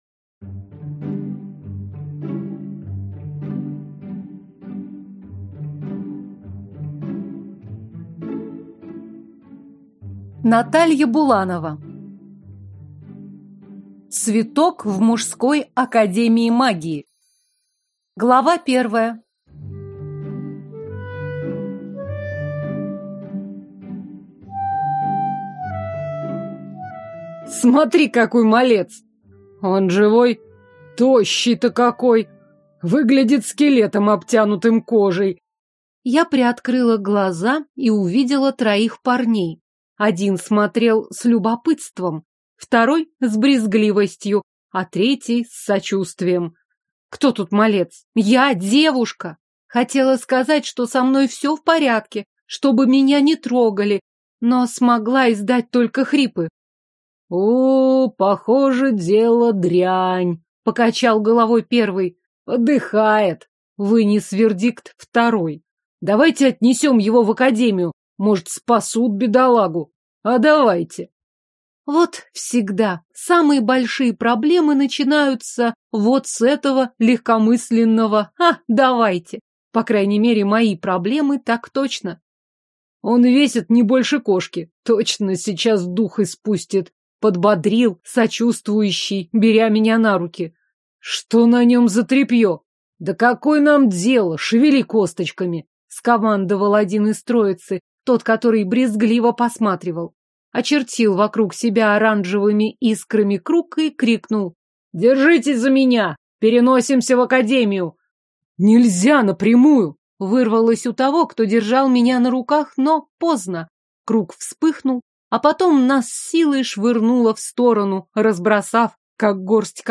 Аудиокнига Цветок в мужской академии магии | Библиотека аудиокниг